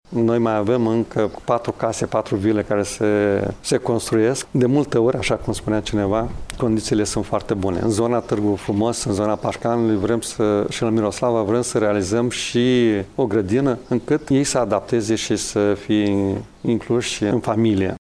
Pe de altă parte, în localtăţile Târgu Frumos, Paşcani şi Miroslava, vor fi construite alte patru vile care vor avea şi grădini în jurul lor, a mai precizat Maricel Popa: